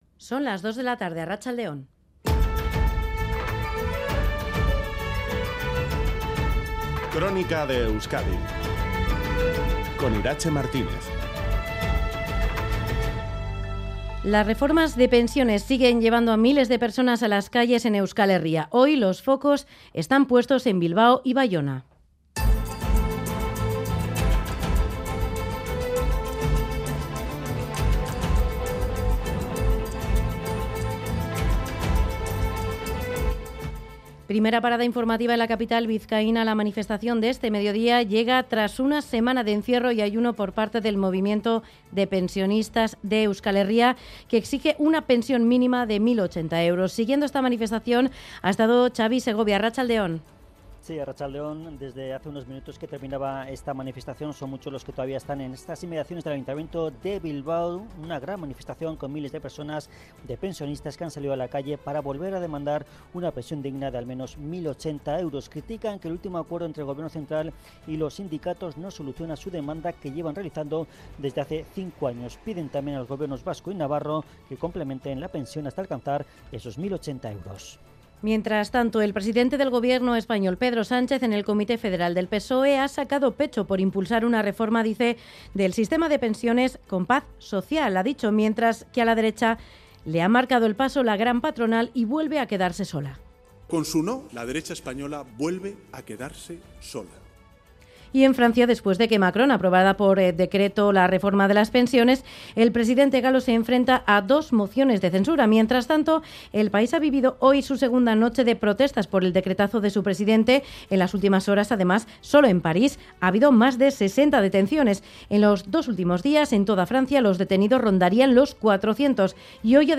La actualidad del fin de semana en el informativo